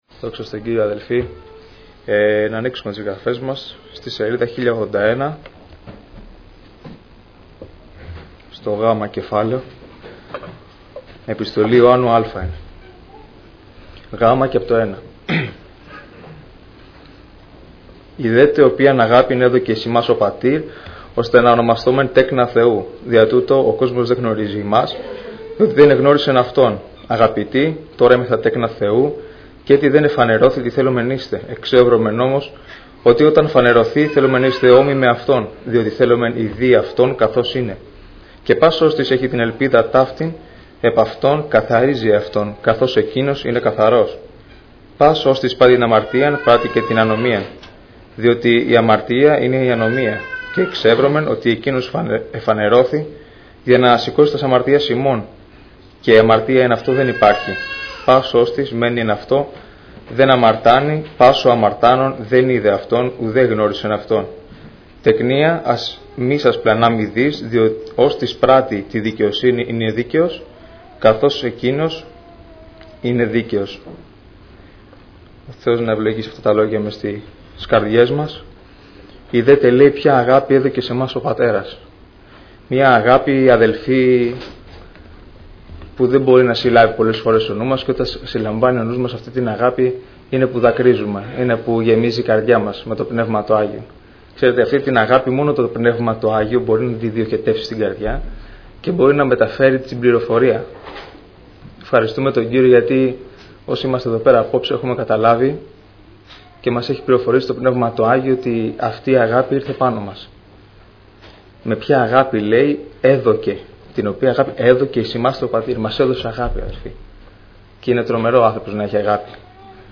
Βραδιά Νεολαίας Ομιλητής: Νεολαία Αγίας Παρασκευής Λεπτομέρειες Σειρά: Κηρύγματα Ημερομηνία: Κυριακή, 04 Οκτωβρίου 2015 Εμφανίσεις: 375 Γραφή: 1 Ιωάννη 3:1-3:7 Λήψη ήχου Λήψη βίντεο